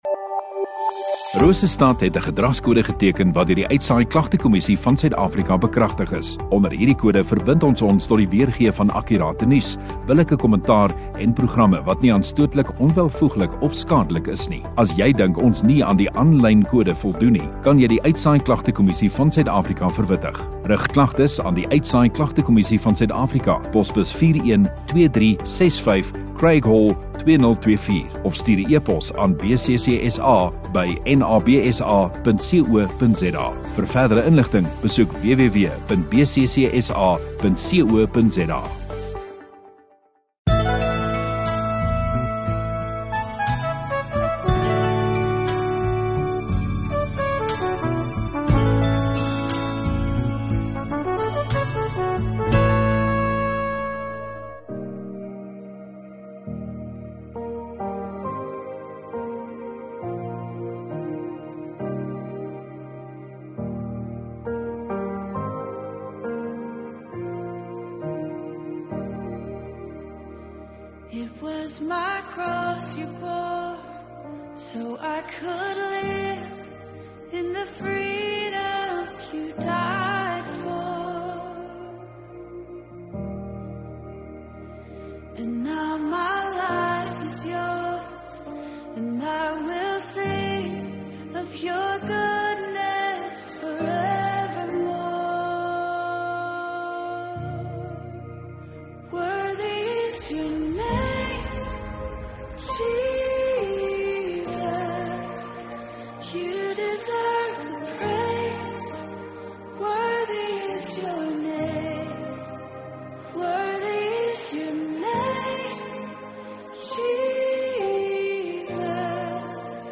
Oggenddiens